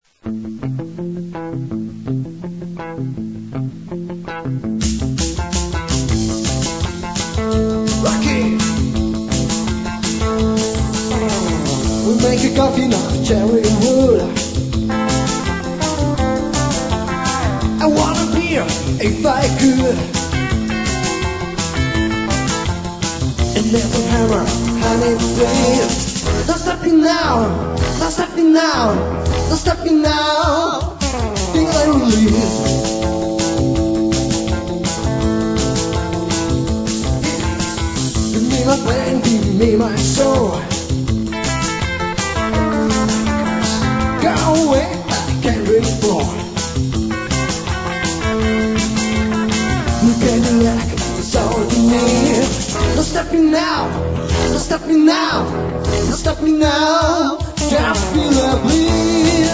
вокал, гитара
контрабас, вокал
ударные